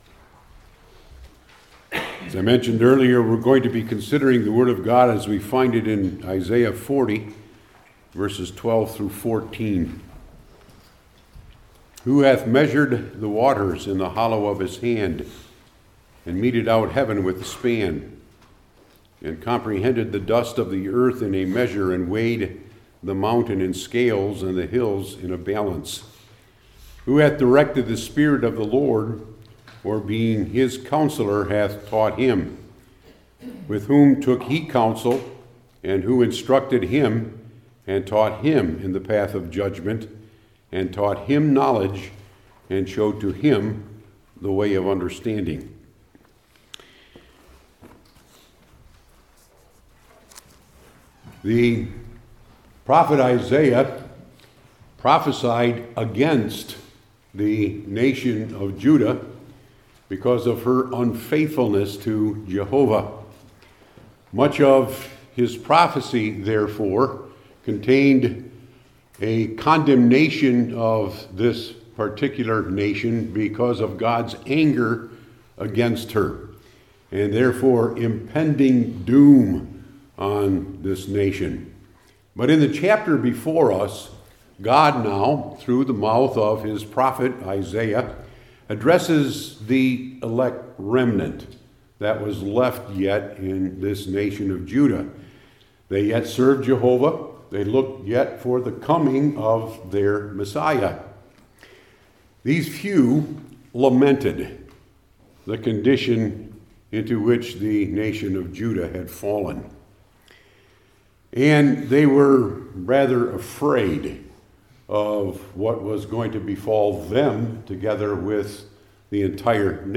Old Testament Sermon Series I. The Idea II.